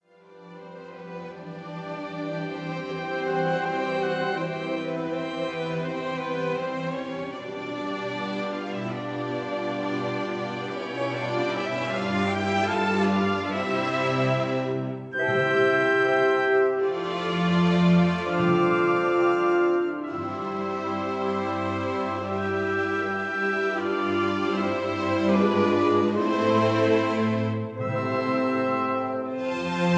adagio molto - allegro con brio